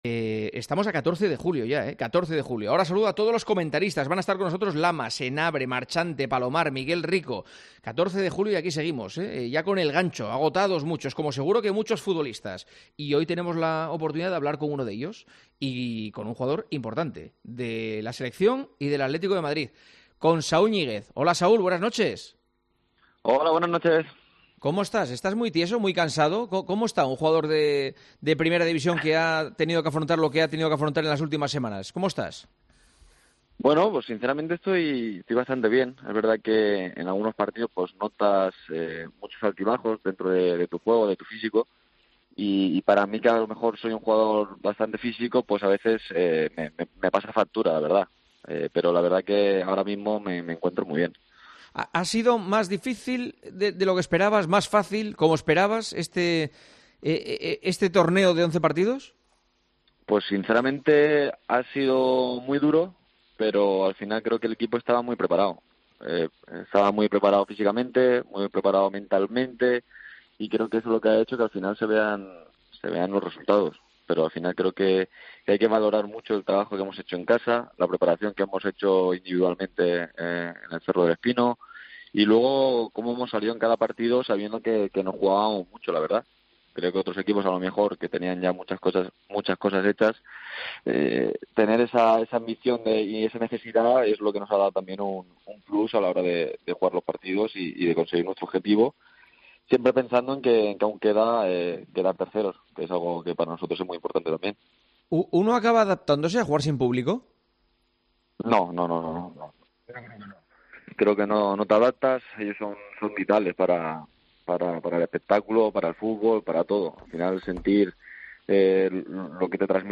AUDIO - ENTREVISTA A SAÚL ÑÍGUEZ, JUGADOR DEL ATLÉTICO DE MADRID, EN EL PARTIDAZO DE COPE